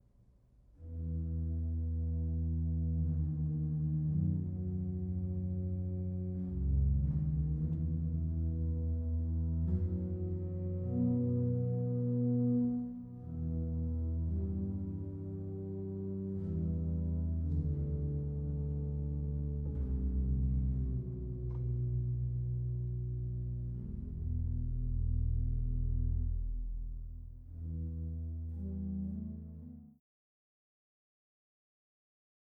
Walcker-Orgel in der St. Annenkirche in Annaberg-Buchholz